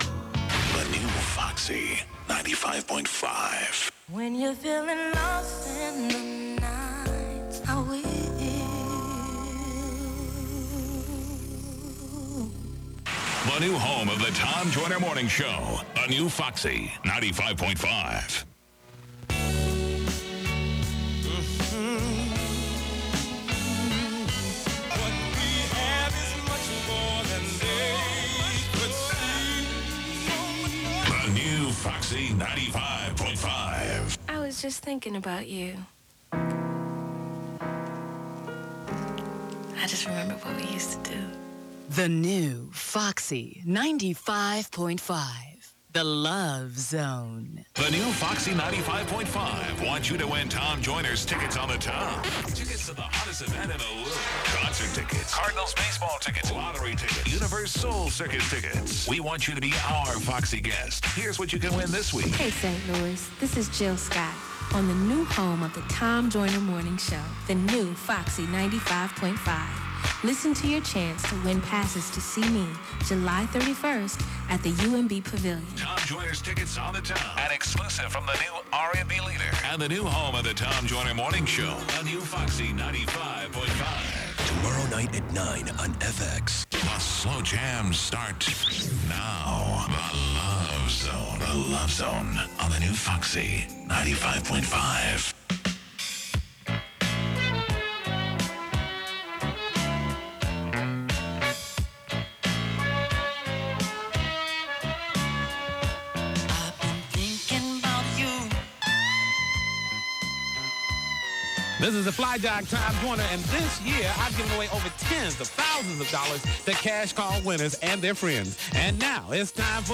WFUN Automation Aircheck · St. Louis Media History Archive